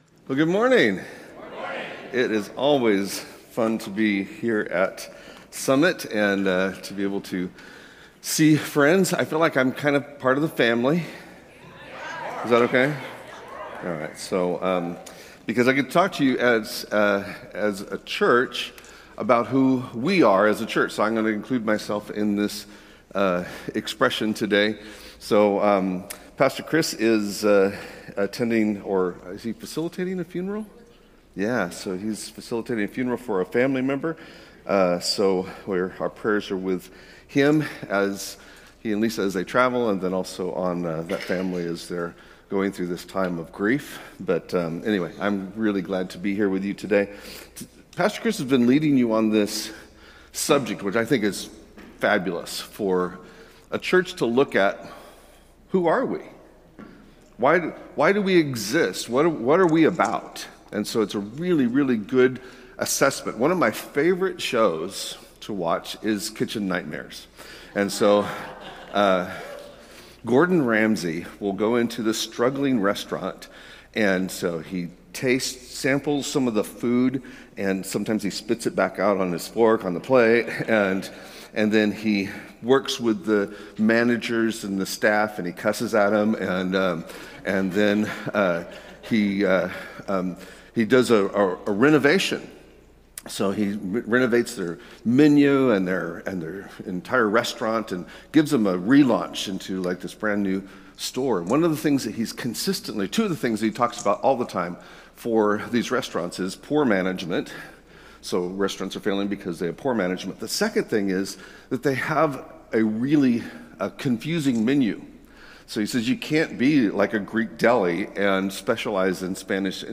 “We Are a Praying Church” What Kind of Church Are We? (Part 8) Guest Speaker